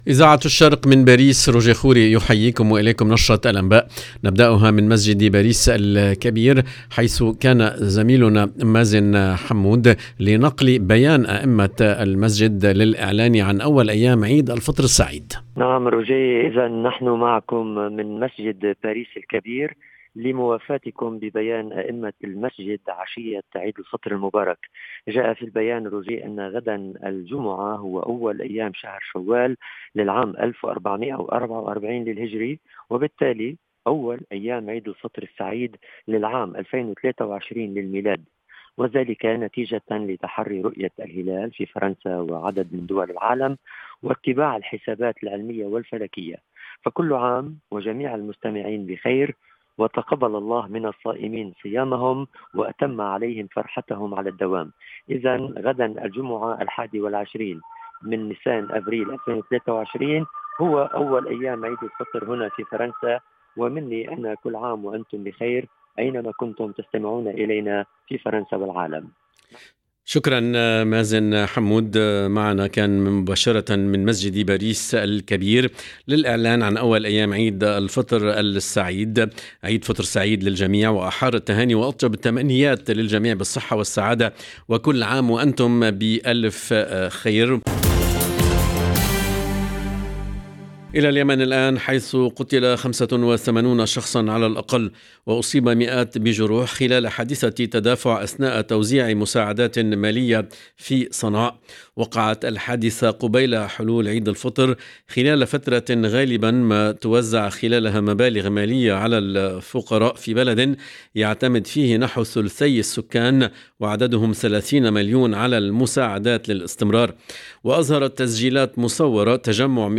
LE JOURNAL EN LANGUE ARABE DU SOIR DU 20/04/23